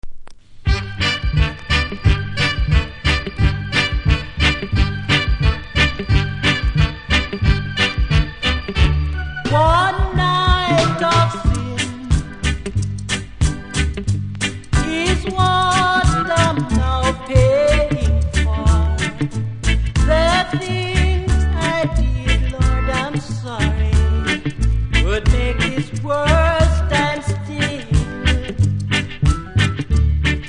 REGGAE 70'S
多少うすキズありますが音は良好なので試聴で確認下さい。